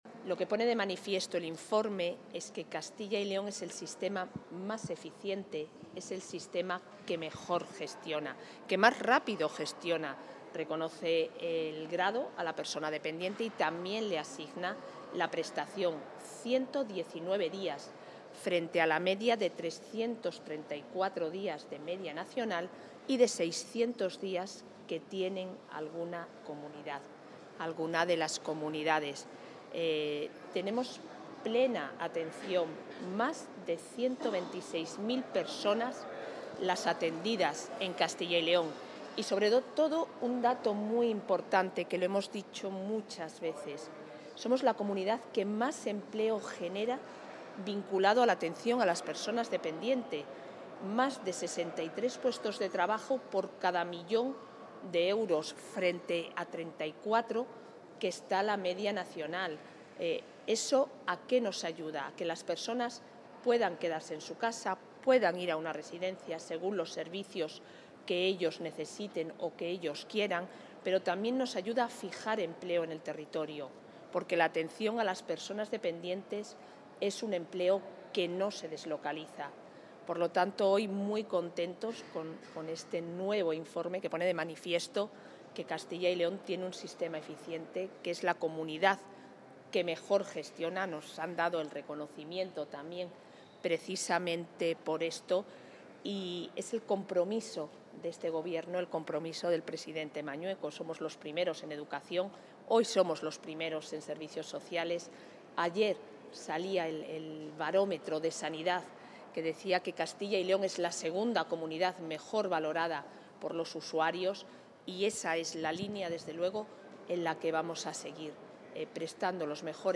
Valoración de la vicepresidenta de la Junta.